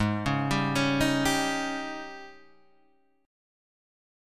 G# Major 7th Suspended 4th